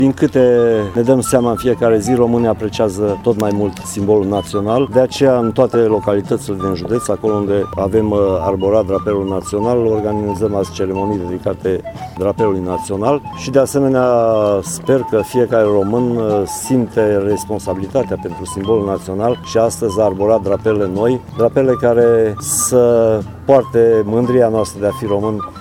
Prefectul județului Mureș, Mircea Dușa, a subliniat importanța prezenței drapelului național nu doar pe sediile instituțiilor publice, dar și la locuințele mureșenilor.